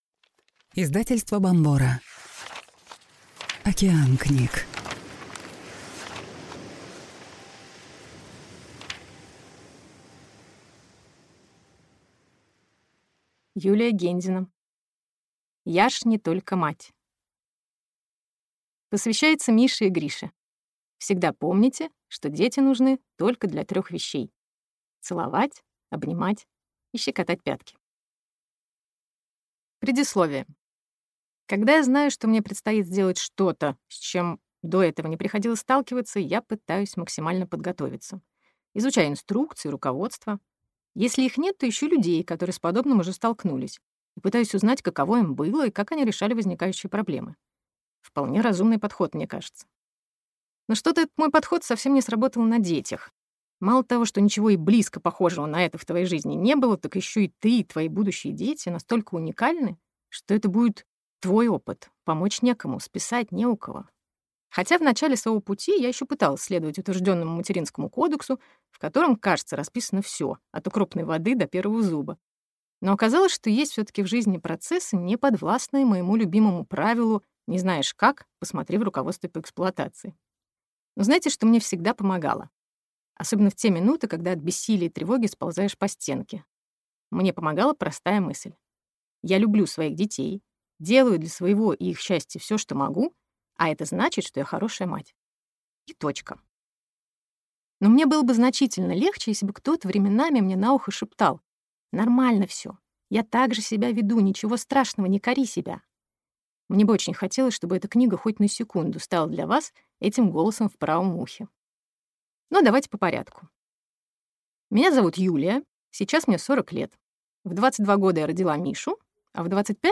Аудиокнига Я ж не только мать. Дарить любовь, не изменяя себе | Библиотека аудиокниг